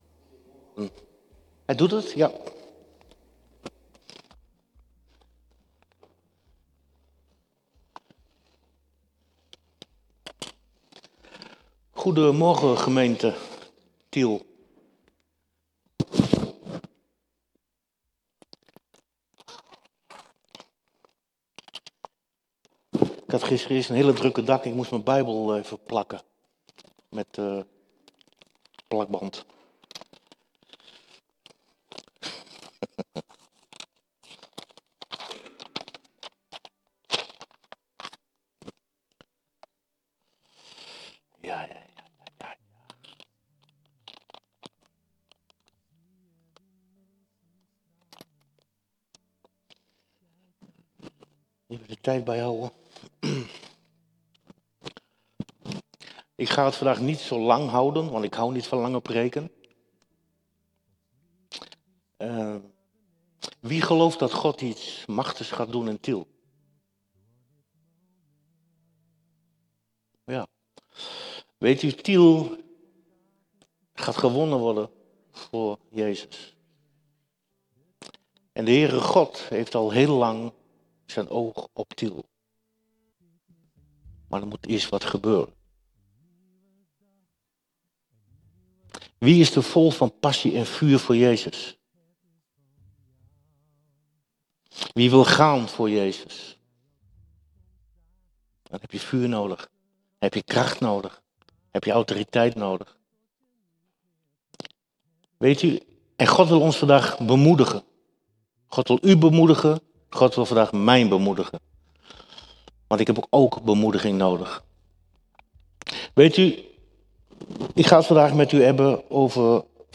De preek